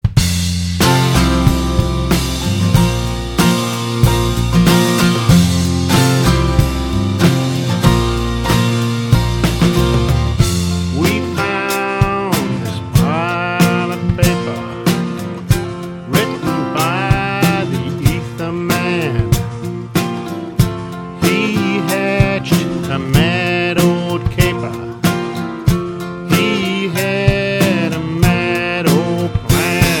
a chance to change pace and tone smoothly